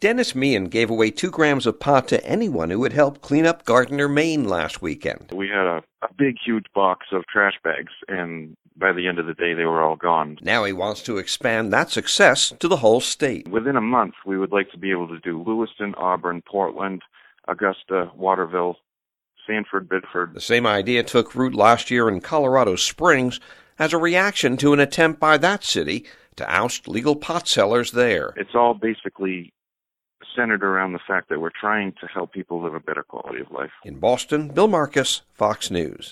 (BOSTON) JULY 27 – A POT DEALER IN A SECOND STATE WHERE THE GIFTING OF CANNABIS IS LEGAL SAYS HE PLANS A STATEWIDE EFFORT TO GIFT MARIJUANA TO VOLUNTEERS WHO HELP CITIES CLEAN UP. FOX NEWS RADIO’S